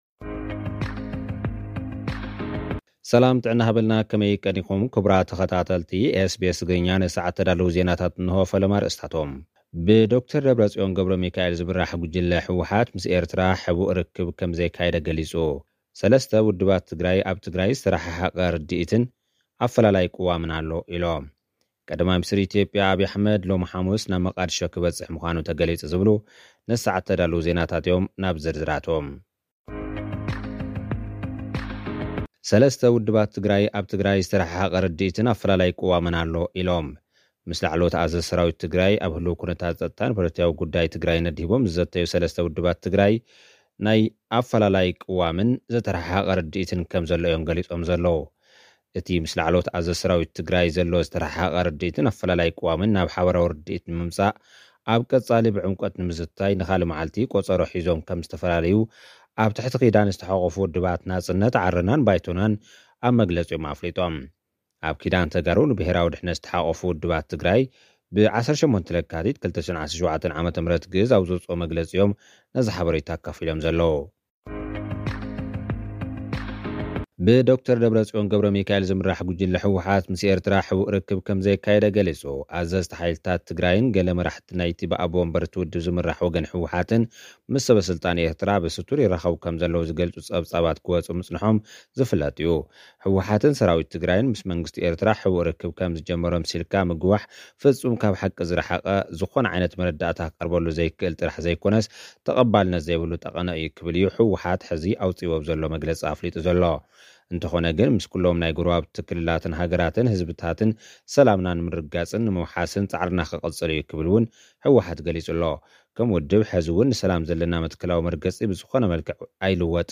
ሚኒስተር ኢትዮጵያ፡ ዶ/ር ኣብራሃም በላይ ወተሃደራት ኤርትራ ካብ ትግራይ ክወጹ ጸዊዑ። (ጸብጻብ)